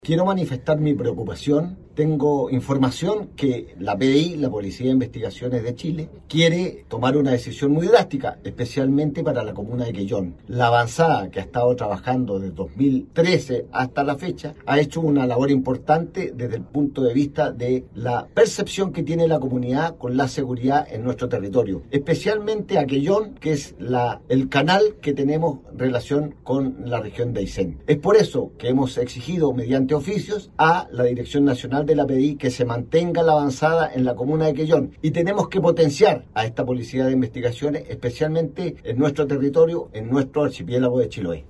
Respecto a esta situación el parlamentario indicó: